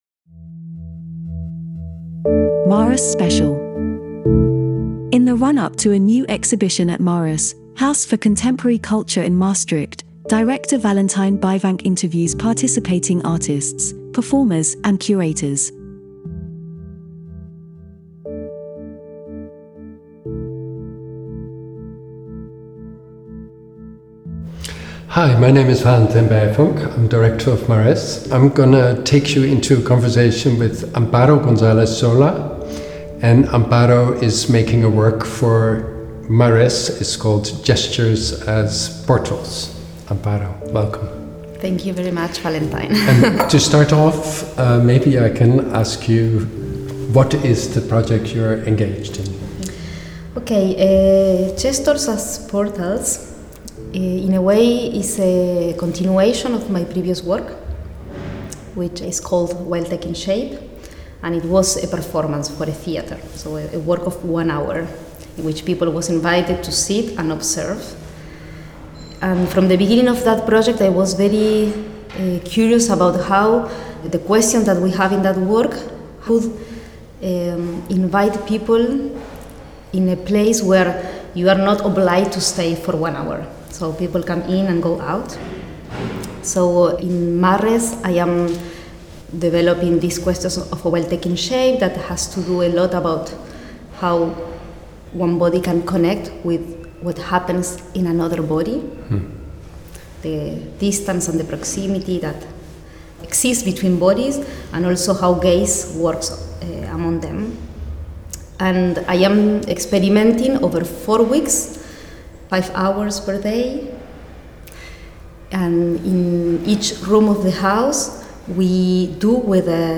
interviews participating artists, performers and curators